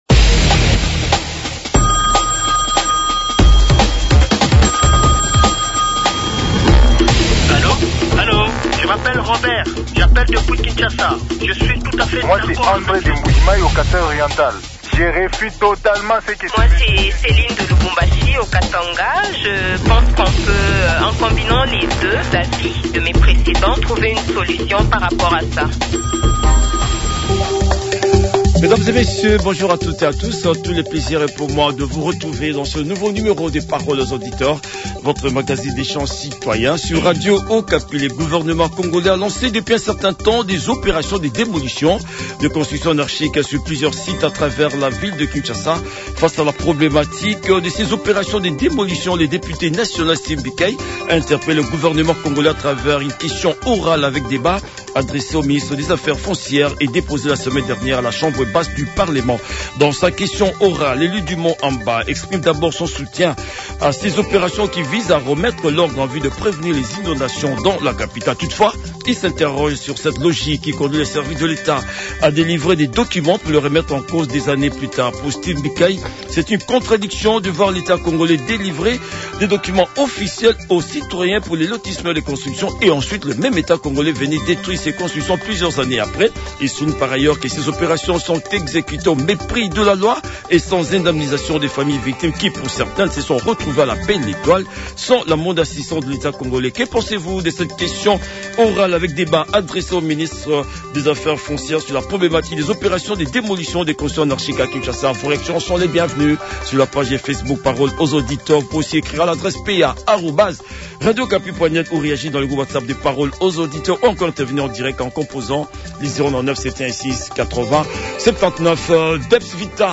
Les auditeurs ont échangé avec Steve Mbikayi, député national élu de Mont Amba à Kinshasa